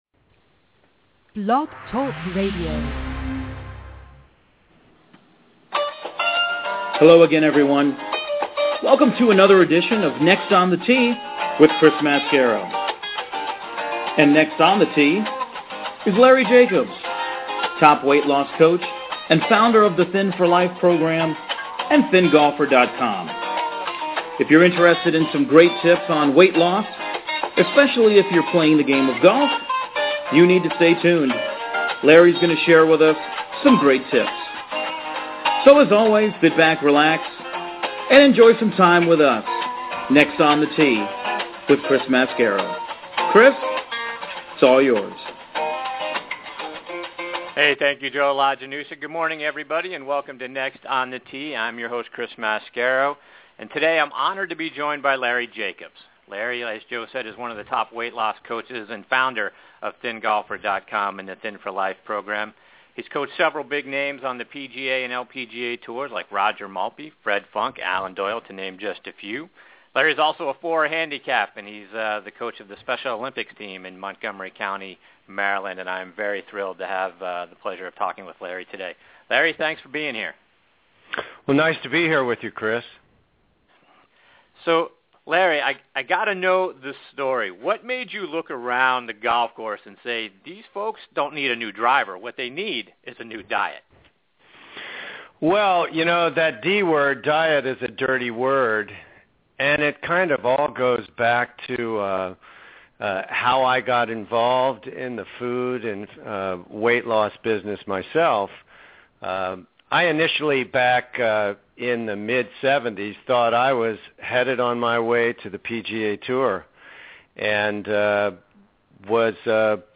New Golf Podcasts with On The Tee on BlogTalkRadio